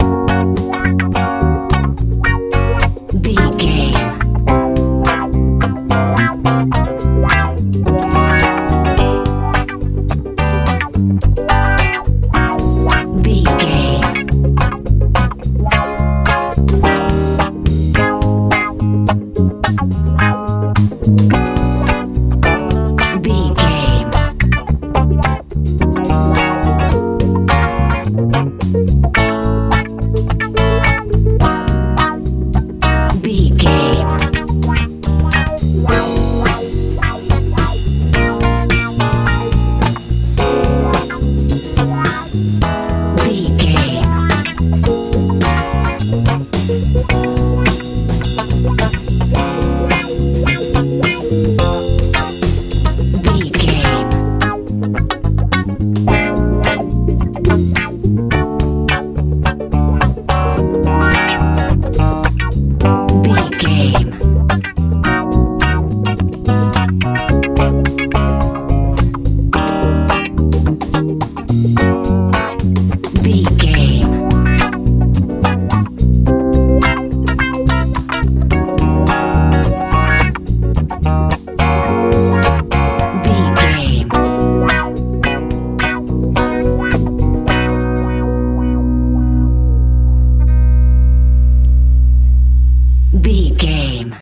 funk feel
Ionian/Major
B♭
groovy
funky
electric guitar
bass guitar
drums
smooth
relaxed